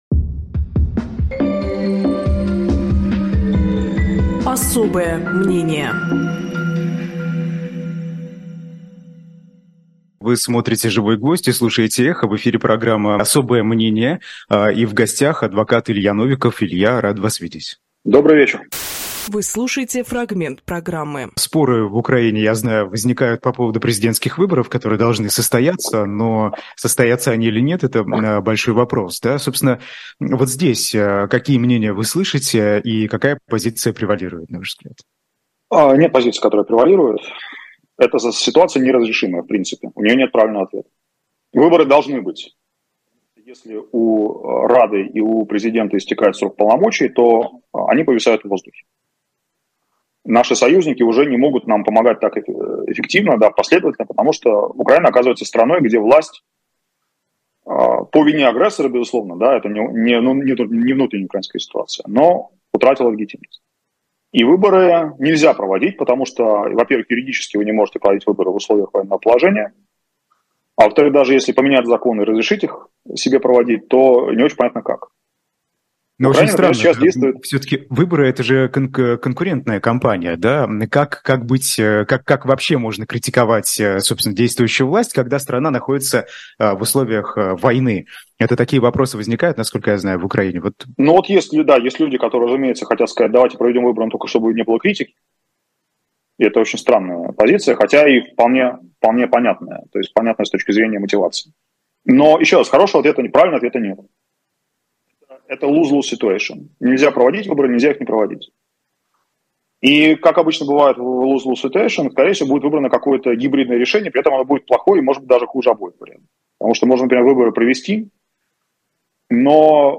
Фрагмент эфира от 24.10